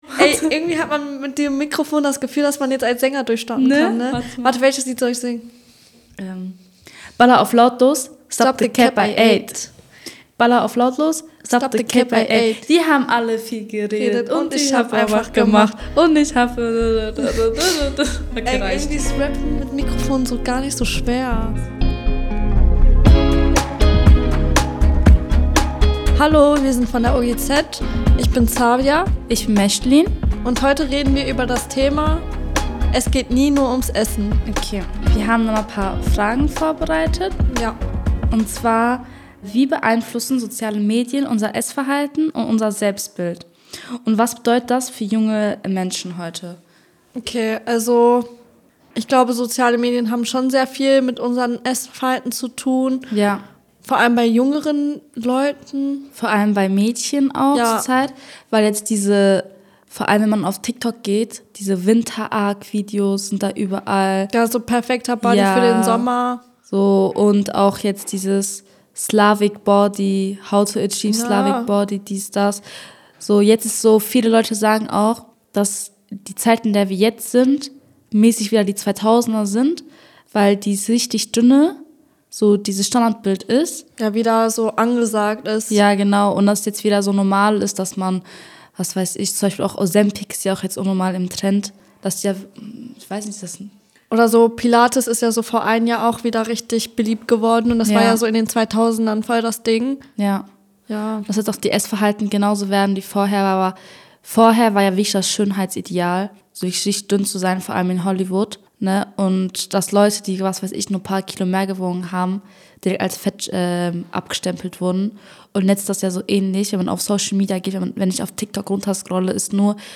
Außerdem sprechen die beiden über Mental Health, Stressessen, kulturelle Hintergründe von Ernährung und warum Essstörungen nicht immer sichtbar sind. Real Talk, persönliche Erfahrungen und klare Aussagen.